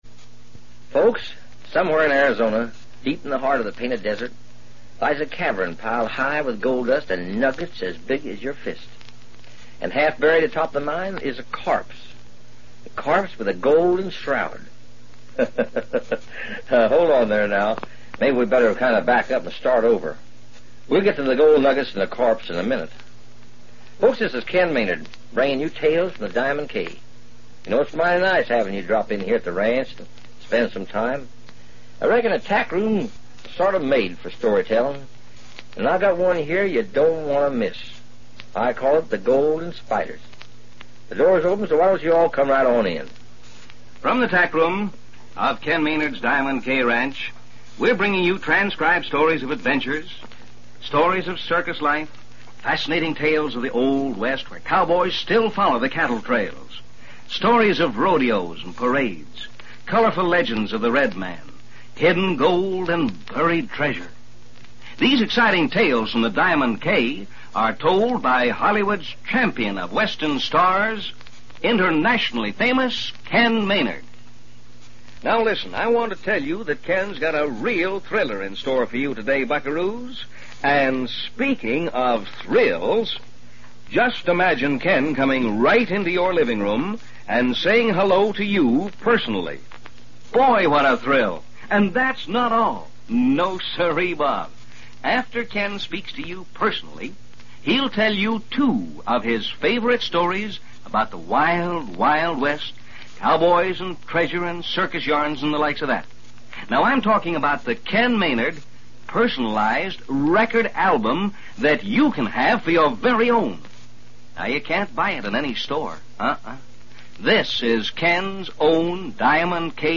"Tales from the Diamond K" was a syndicated radio show aimed at a juvenile audience, broadcasted during the mid-1950s. - The show featured a variety of stories, mostly set in the Old West, and was designed to entertain and educate its young listeners. - Ken Maynard, a pioneer singing cowboy and film star, hosted the show, introducing a different story each day.